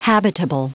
Prononciation du mot habitable en anglais (fichier audio)
Prononciation du mot : habitable